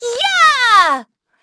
Xerah-Vox_Attack2.wav